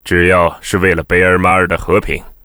文件 文件历史 文件用途 全域文件用途 Bk_amb.ogg （Ogg Vorbis声音文件，长度2.4秒，108 kbps，文件大小：32 KB） 源地址:游戏语音 文件历史 点击某个日期/时间查看对应时刻的文件。